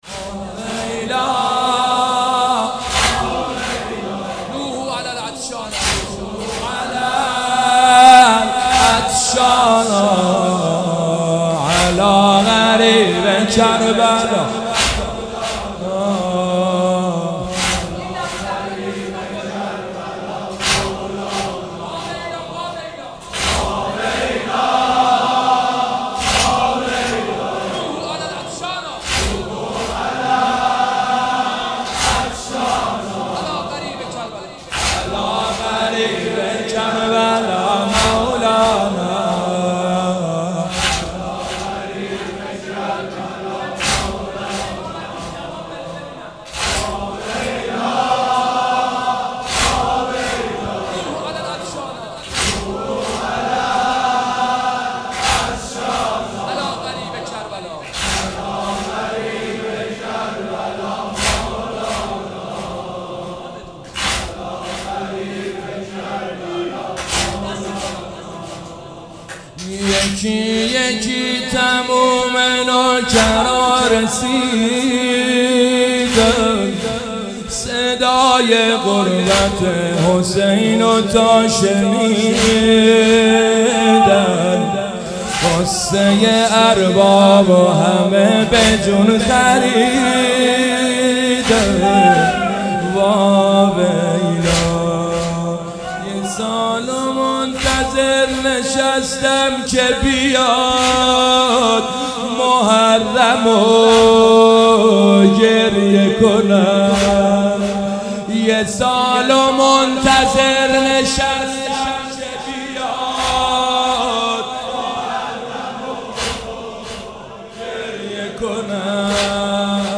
حاج حسین سییب سرخی/شب دوم محرم97